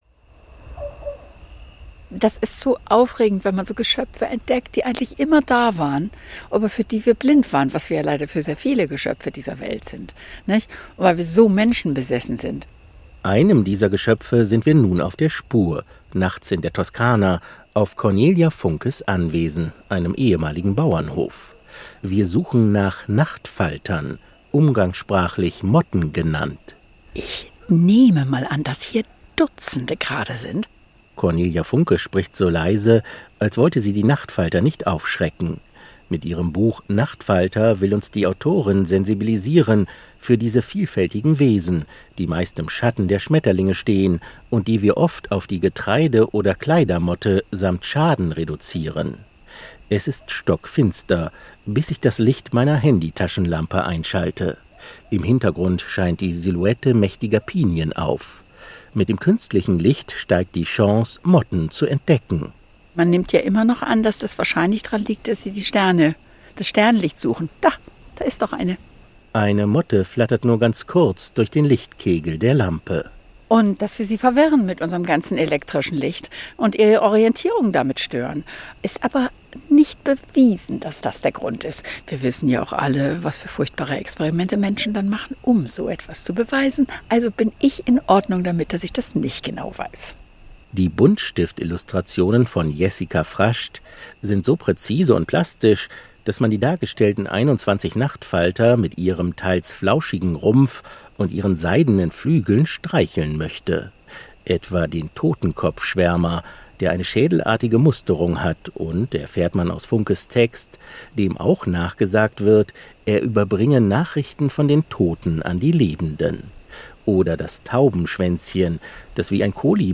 Nachts in der Toskana, auf Cornelia Funkes Anwesen, einem ehemaligen Bauernhof.
Cornelia Funke spricht so leise, als wollte sie die Nachtfalter nicht aufschrecken.
Nachtfalter-Suche in Cornelia Funkes Garten
Cornelia Funke hört ein Käuzchen rufen. „Der frisst natürlich Motten“, lacht die Autorin.